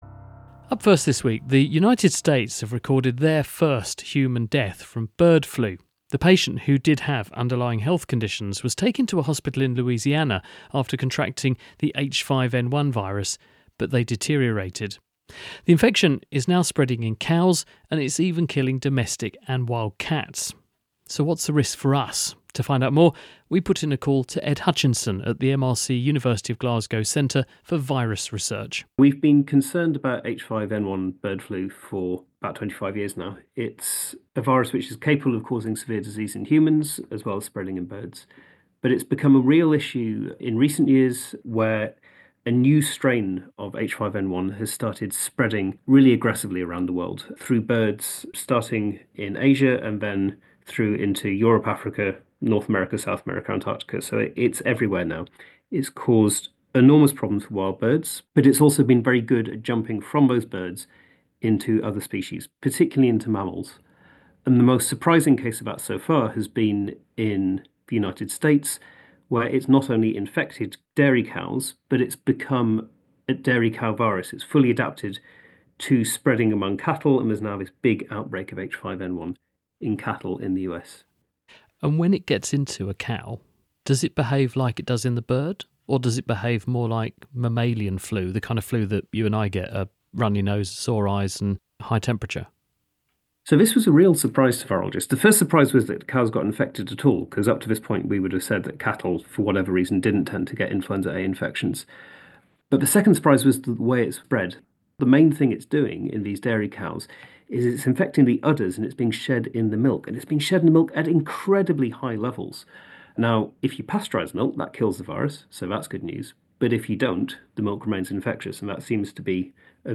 2. Interviews